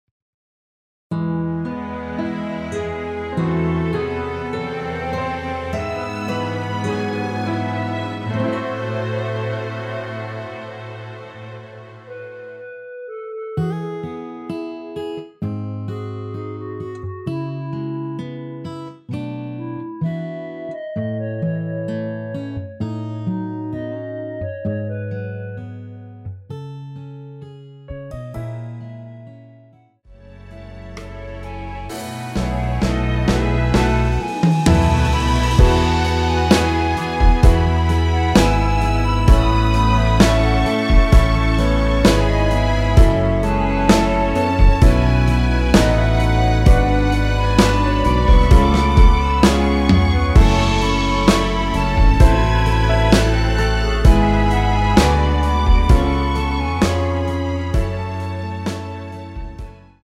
원키에서(+2)올린 멜로디 포함된 MR입니다.(미리듣기 확인)
◈ 곡명 옆 (-1)은 반음 내림, (+1)은 반음 올림 입니다.
앞부분30초, 뒷부분30초씩 편집해서 올려 드리고 있습니다.
중간에 음이 끈어지고 다시 나오는 이유는